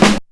Snare (64).wav